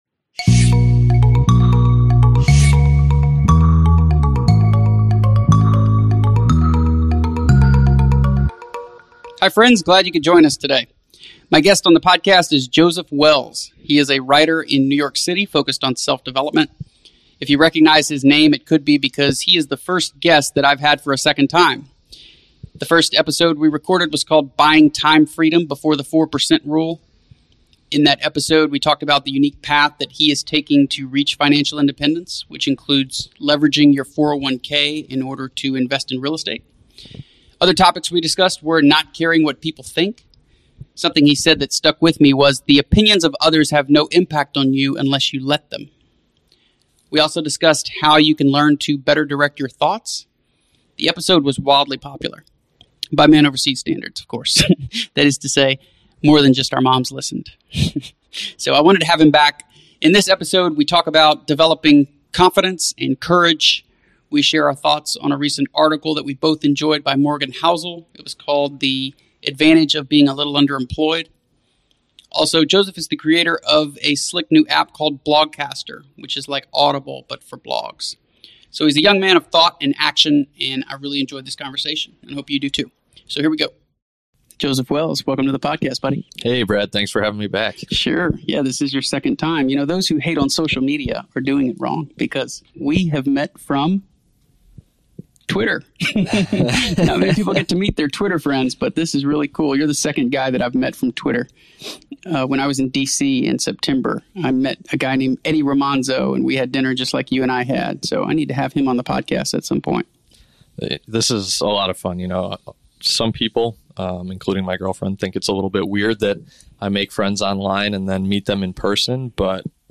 So I upgraded my equipment for an enhanced audio experience, flew to New York City and invited back on the show a listener-favorite.